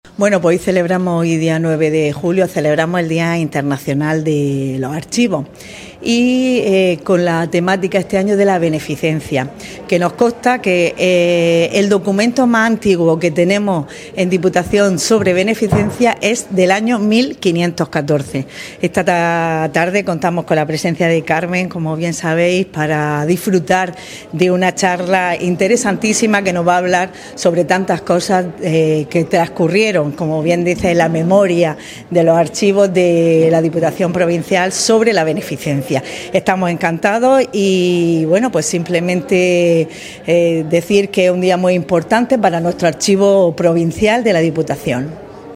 La diputada provincial, Esther Álvarez, ha dado la bienvenida a todos los asistentes que han acudido a la conferencia celebrada en el Museo del Realismo Español Contemporáneo (MUREC)
Audio-1.-Esther-Alvarez.-Conferencia-Archivos.mp3